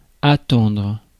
Ääntäminen
IPA: [a.tɑ̃dʁ]